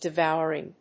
発音記号
• / ‐vάʊ(ə)rɪŋ(米国英語)
• / dɪˈvaʊɜ:ɪŋ(英国英語)